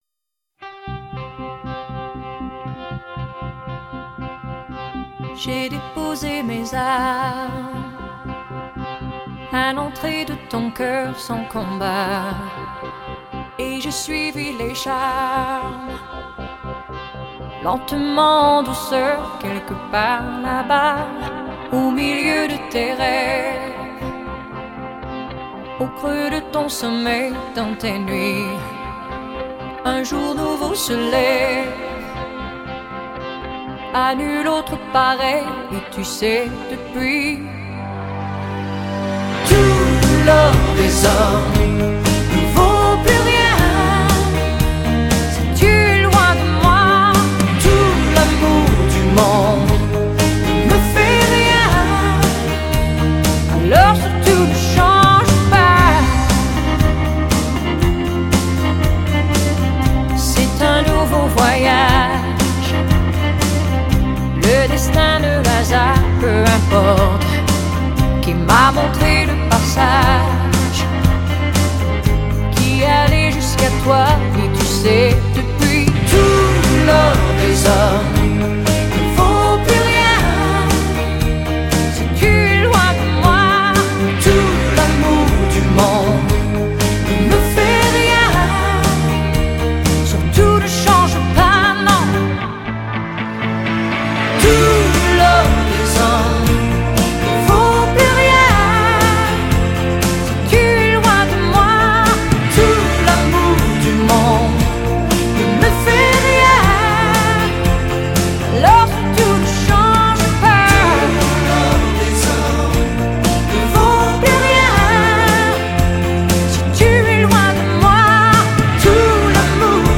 她的法语歌声中，流动着法式优雅及感性情怀。